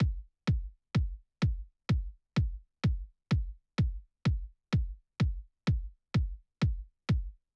kick2.wav